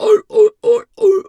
seal_walrus_2_bark_05.wav